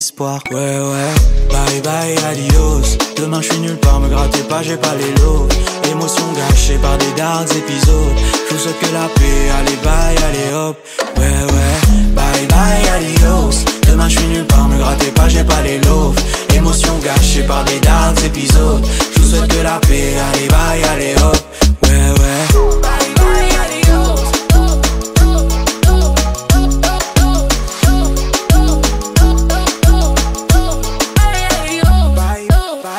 Catégorie Rap